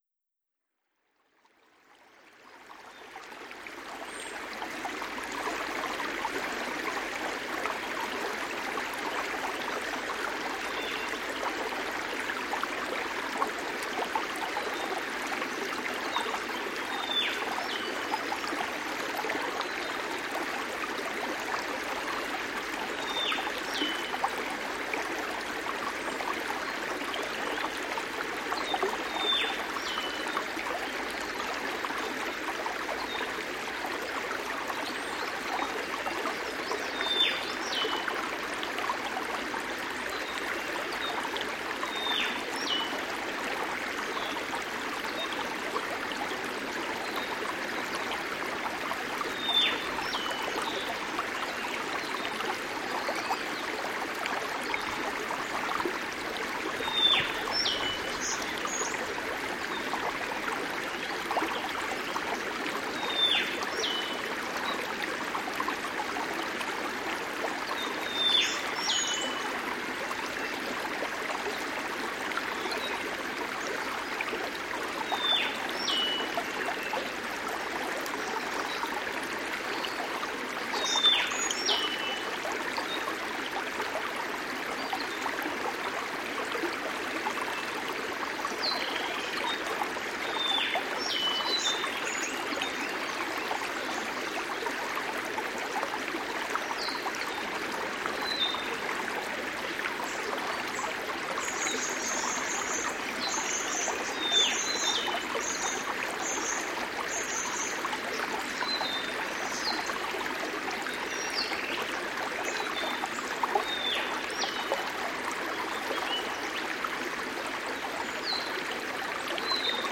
• small river and olive whistler birds.wav
small_river_and_Olive_Whistler_birds_7Wz.wav